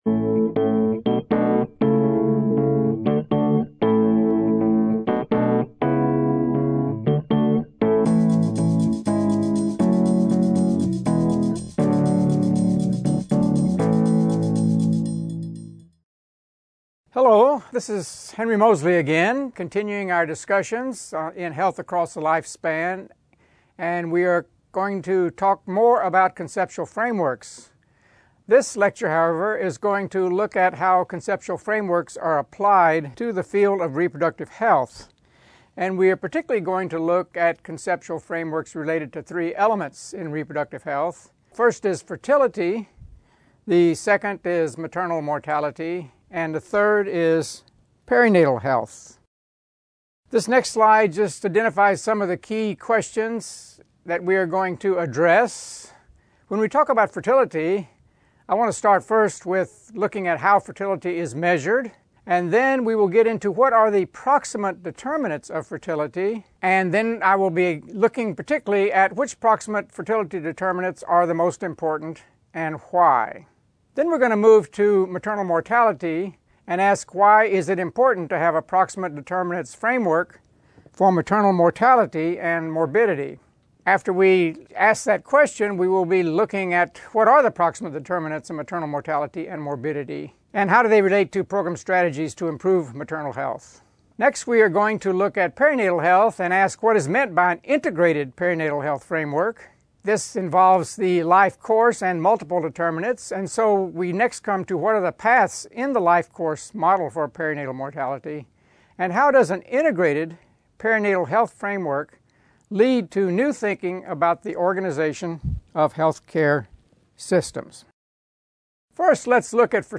Full Course (Internet) John Hopkins Bloomberg School of Public Health John Hopkins (BSPH) Fertility: The Davis-Blake Proximate Determinants... Audio Lecture